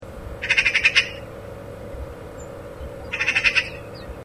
Чей это голос?
harakas.ogg